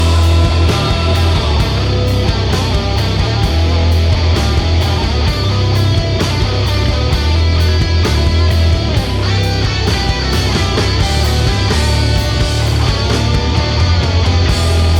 В этом куске гитары строят?